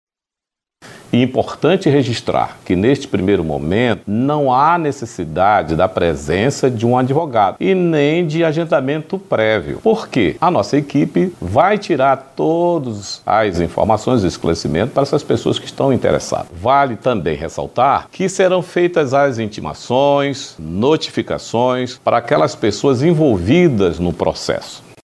O corregedor do TRT11, desembargador Alberto Bezerra de Melo, destaca que para ser atendido, é necessário levar documentos de identificação (CPF, Carteira de Trabalho, identidade, PIS ou NIT), além do nome e endereço da empresa envolvida e documentos relacionados ao caso.
SONORA-1-DESEMBARGADOR.mp3